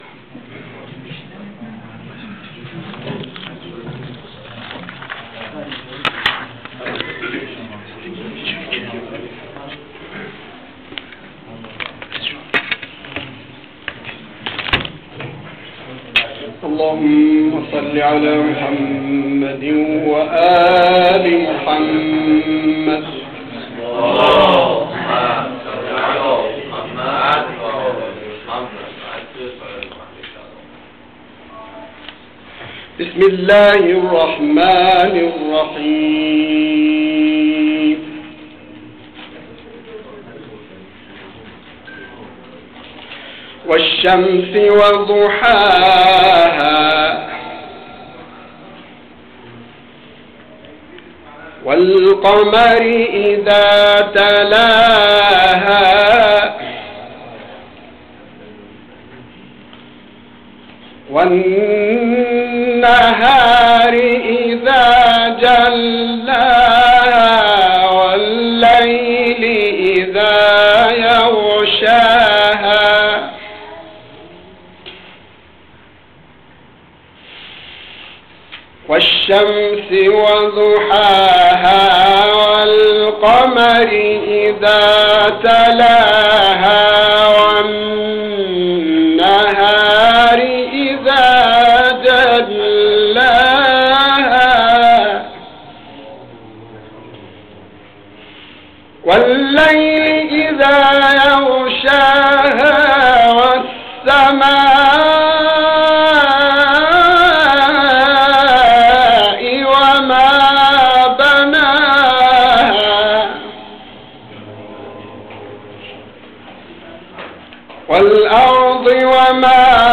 نشست علمی هفدهم
محل برگزاری: موسسه آموزش عالی اخلاق و تربیت